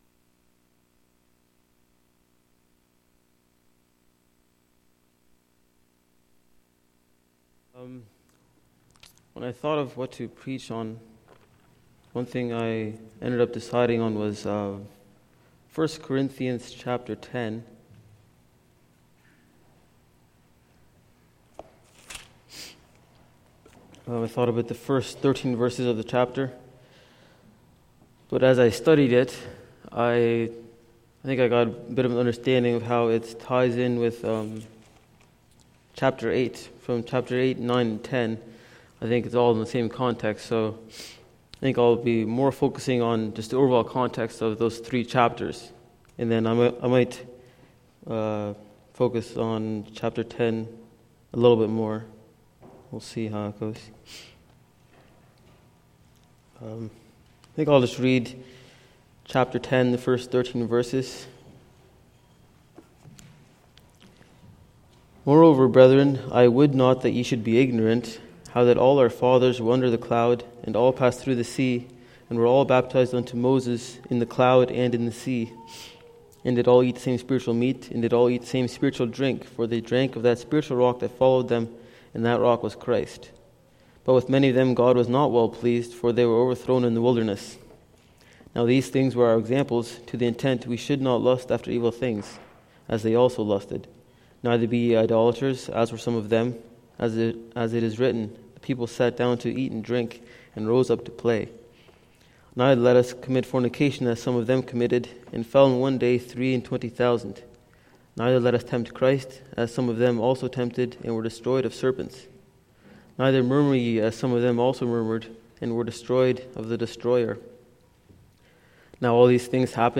Sunday Morning Sermon Service Type